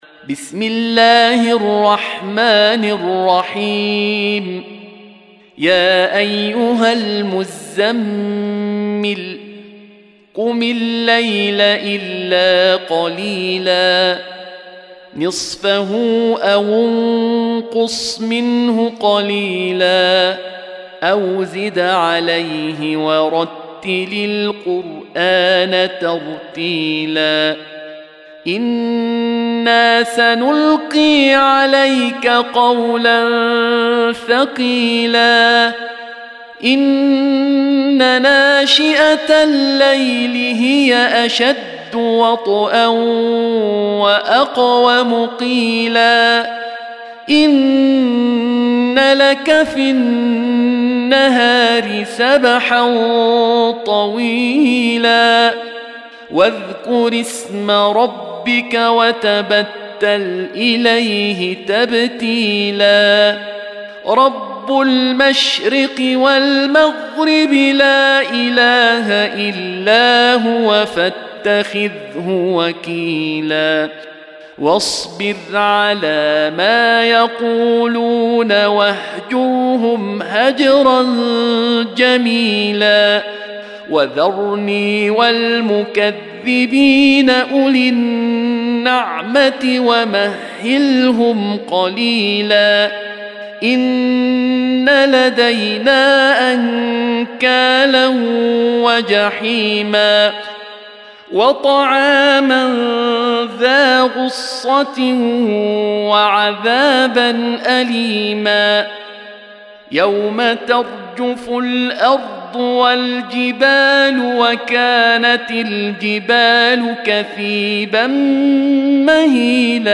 73. Surah Al-Muzzammil سورة المزّمّل Audio Quran Tarteel Recitation
Surah Sequence تتابع السورة Download Surah حمّل السورة Reciting Murattalah Audio for 73. Surah Al-Muzzammil سورة المزّمّل N.B *Surah Includes Al-Basmalah Reciters Sequents تتابع التلاوات Reciters Repeats تكرار التلاوات